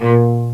CELLO2.WAV